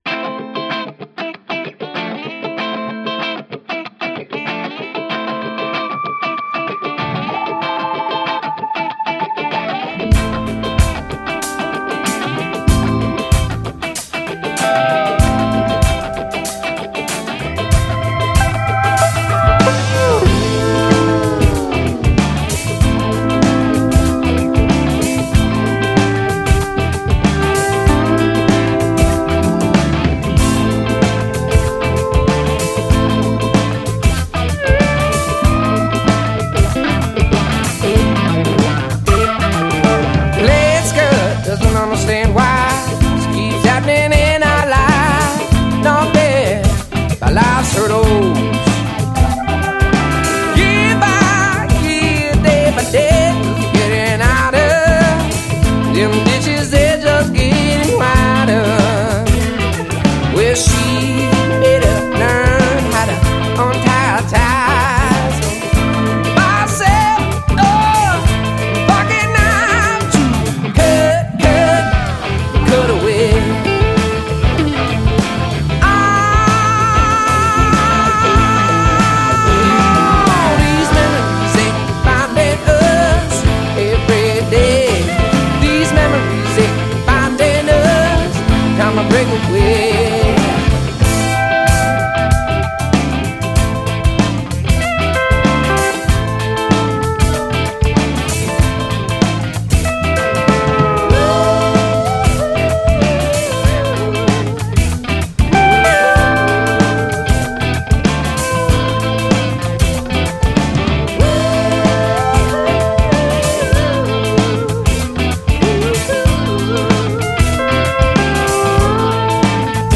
Soulful Americana music in the making